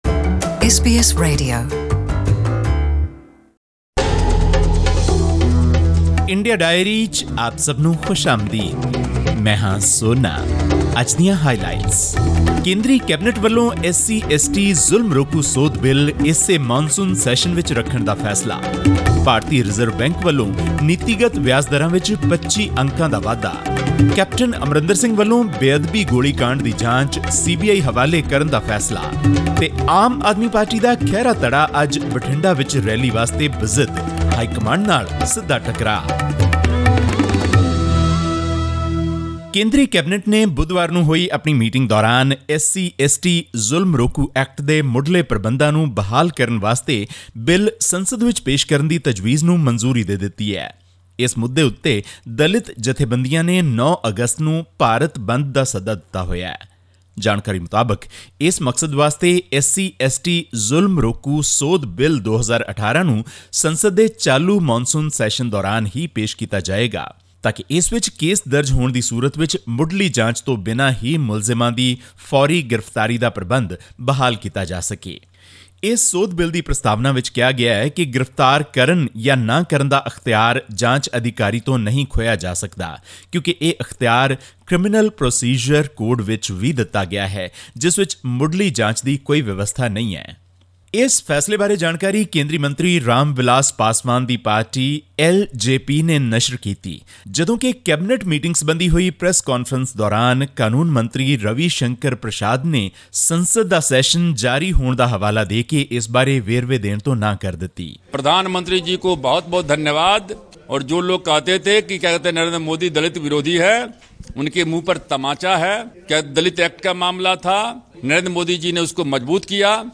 Hear more about this, and other news stories from India, in our weekly report, which also includes news about the central Cabinet's decision to clear amendments to the SC/ST (Prevention of Atrocities) Act, overturning a previous decision in March by the Supreme Court of India.